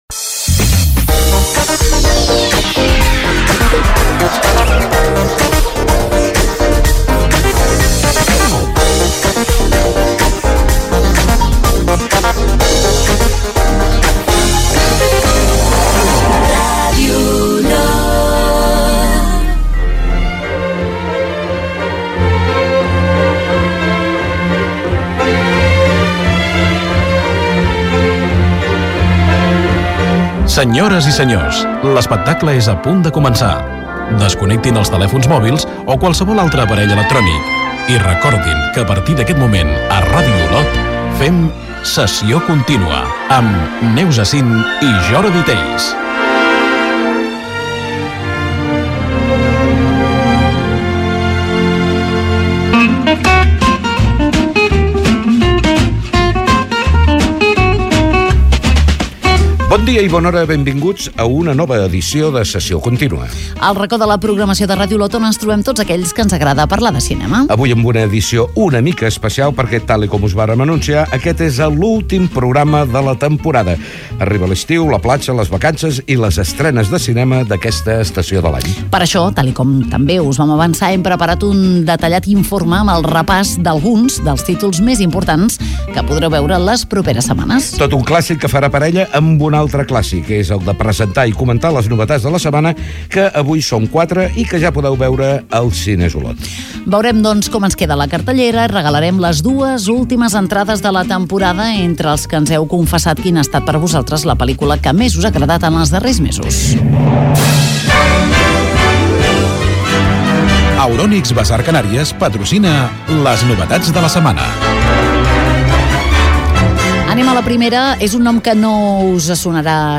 Indicatiu de l'emissora, careta del programa, presentació de l'última edició de la temporada amb el sumari, novetat de la setmana,